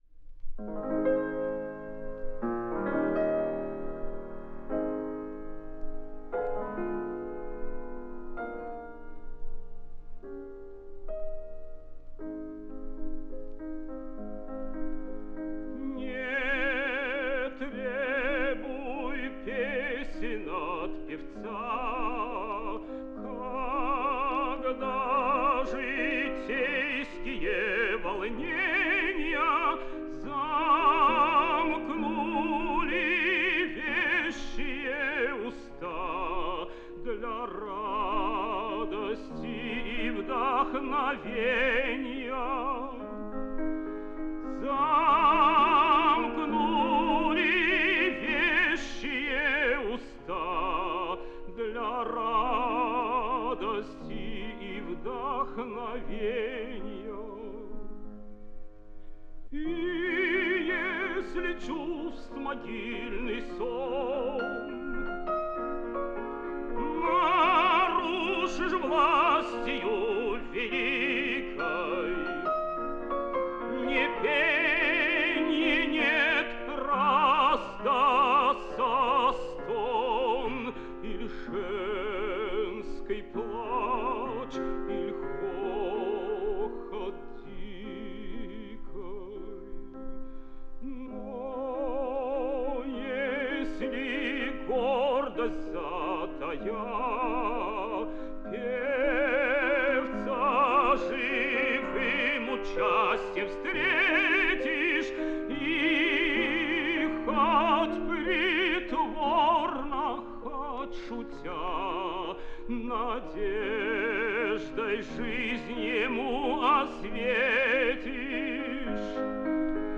тенор
ф-но